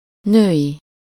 Ääntäminen
Ääntäminen France: IPA: /fe.mi.nɛ̃/ Paris Haettu sana löytyi näillä lähdekielillä: ranska Käännös Ääninäyte 1. női 2. nőnemű 3. nőnem 4. nőies 5. nőstény Suku: m .